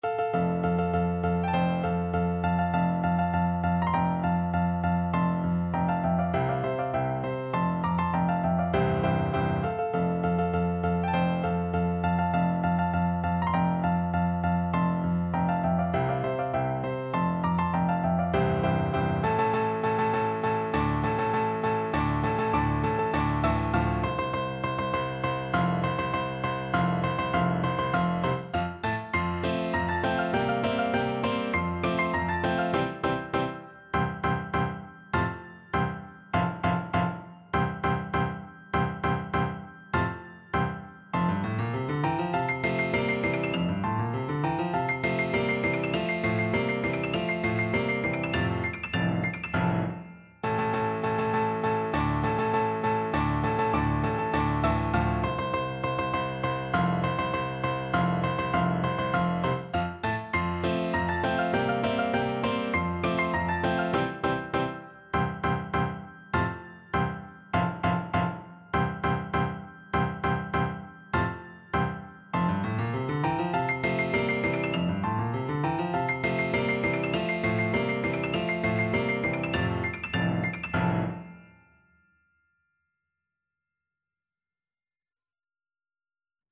Piano version
No parts available for this pieces as it is for solo piano.
March = c.100
2/2 (View more 2/2 Music)
Piano  (View more Advanced Piano Music)
Classical (View more Classical Piano Music)